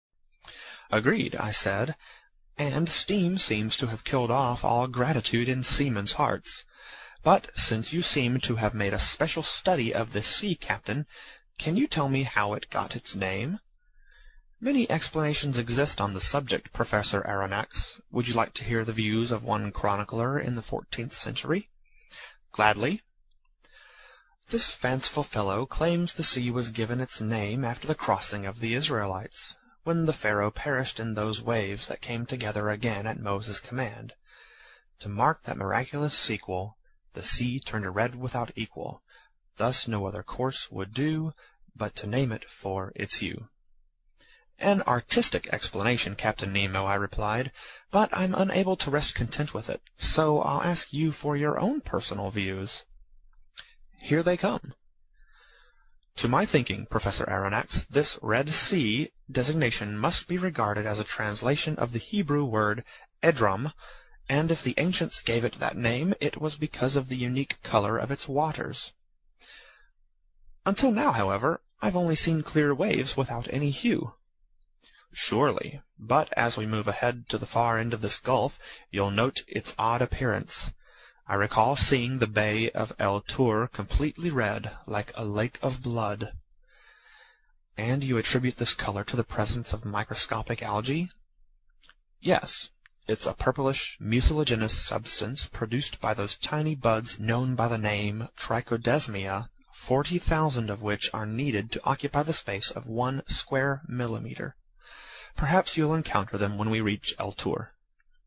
英语听书《海底两万里》第348期 第23章 珊瑚王国(45) 听力文件下载—在线英语听力室